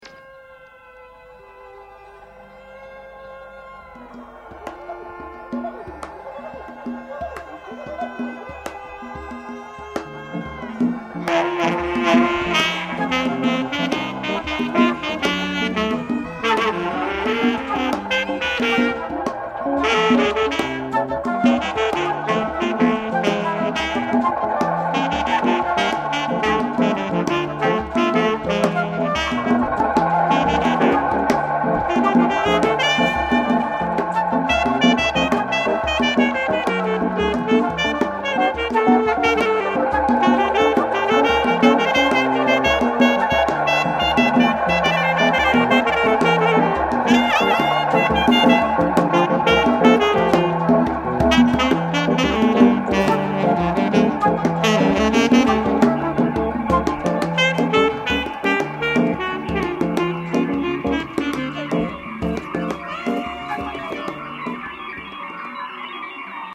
sax ténor, Fender Rhodes, voix, percussions
flûtes, sax soprano & alto, appeaux, voix,